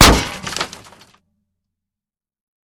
5caee9fba5 Divergent / mods / JSRS Sound Mod / gamedata / sounds / material / bullet / collide / metall04gr.ogg 60 KiB (Stored with Git LFS) Raw History